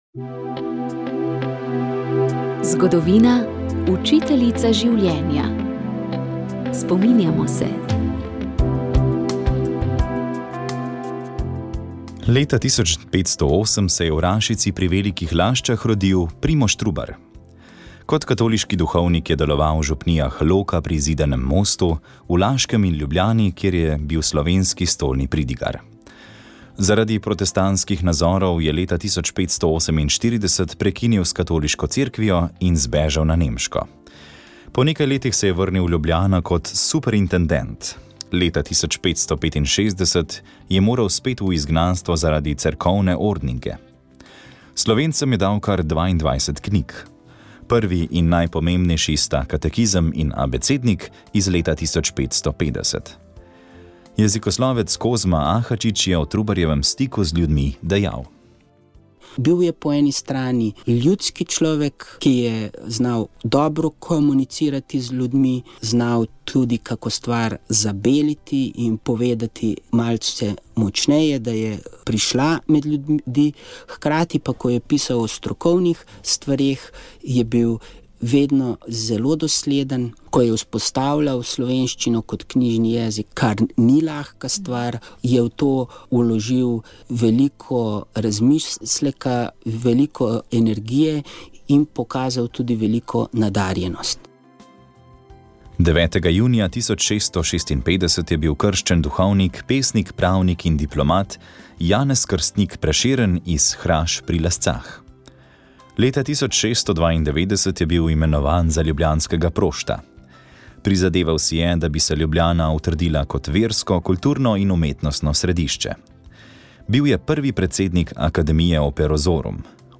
Sv. maša iz stolne cerkve sv. Janeza Krstnika v Mariboru 24. 9.
Na god blaženega Antona Martina Slomška je slovesno sveto mašo daroval upokojeni mariborski nadškof Marjan Turnšek, s petjem so bogoslužje bogatile Šolske sestre.